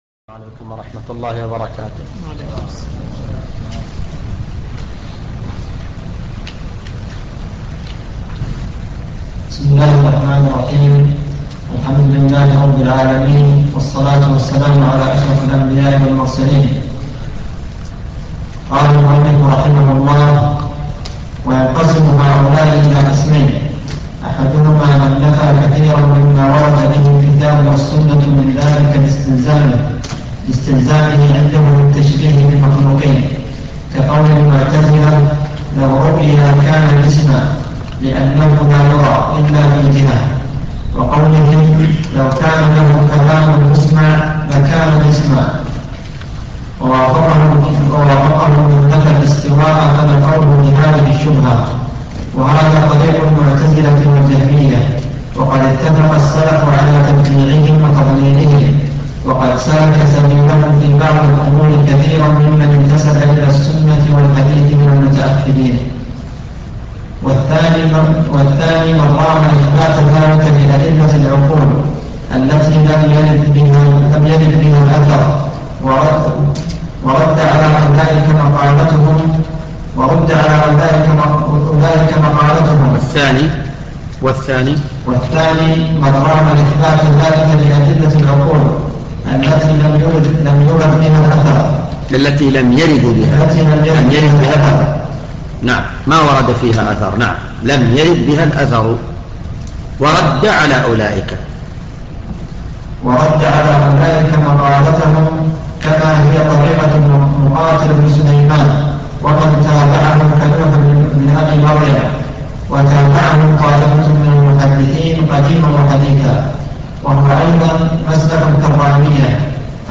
الدرس الثالث